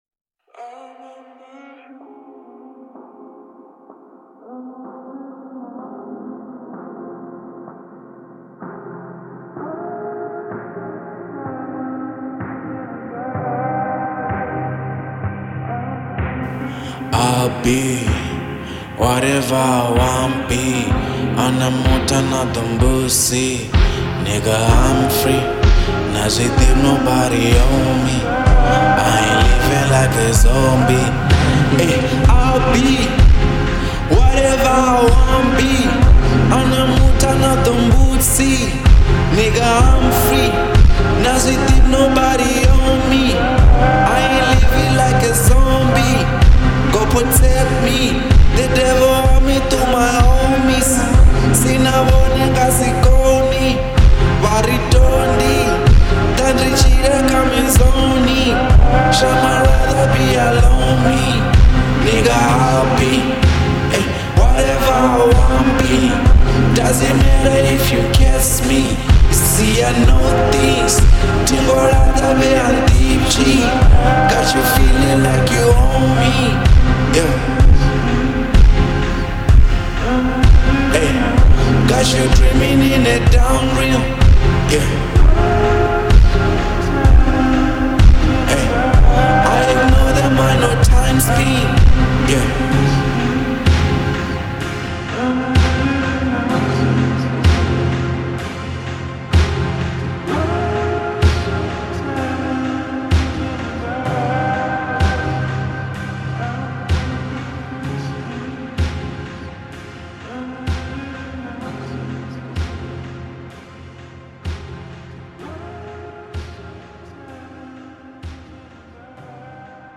02:02 Genre : Venrap Size